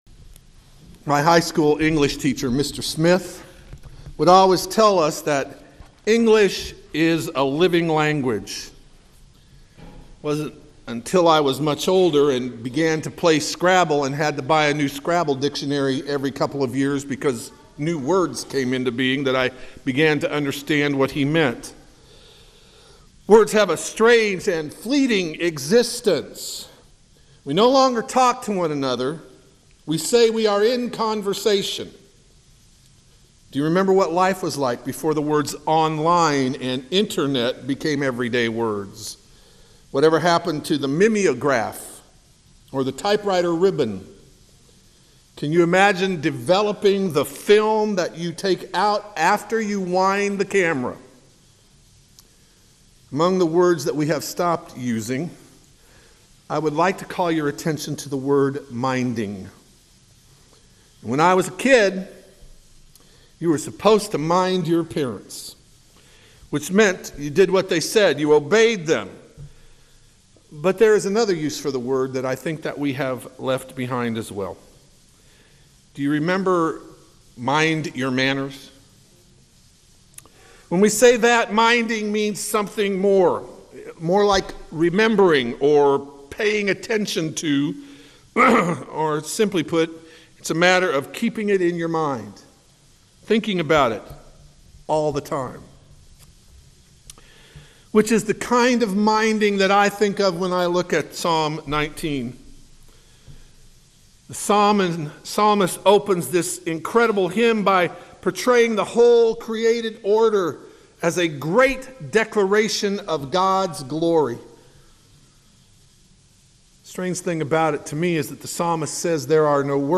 First Presbyterian Church of Ilion, NY